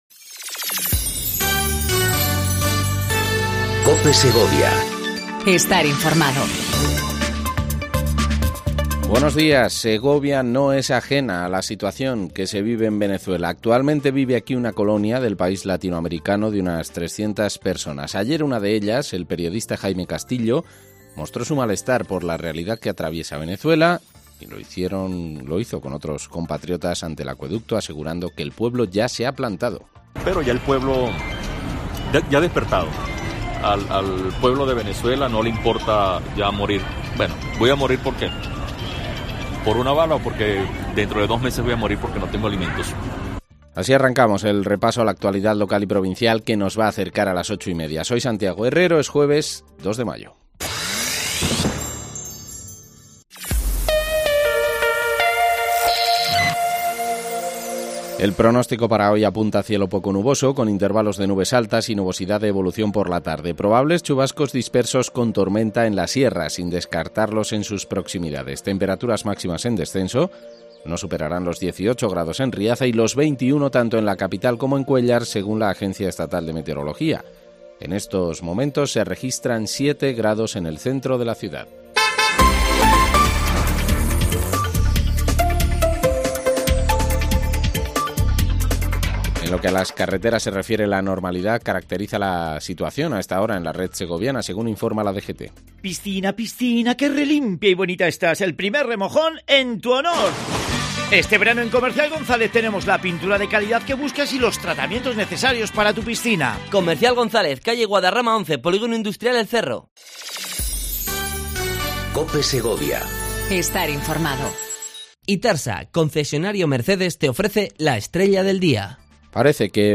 INFORMATIVO 08:25 COPE SEGOVIA 02/05/19
AUDIO: Segundo informativo local en cope segovia